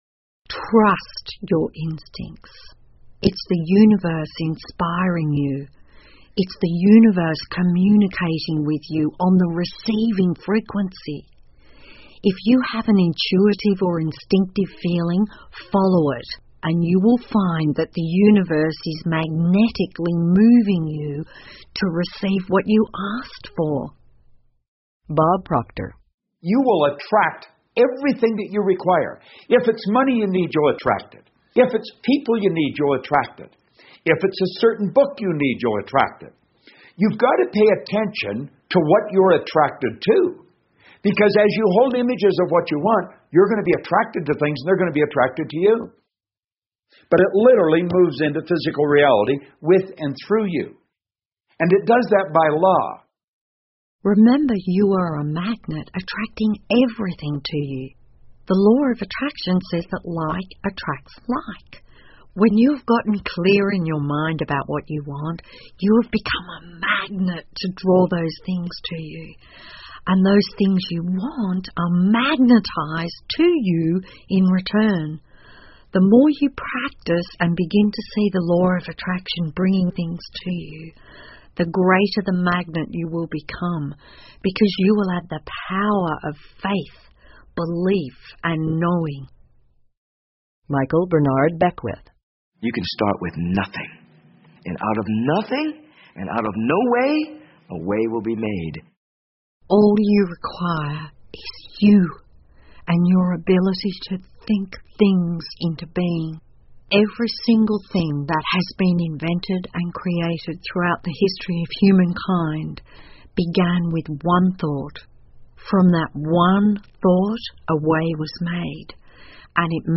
有声畅销书-秘密 2-07 Trust Your Instincts 听力文件下载—在线英语听力室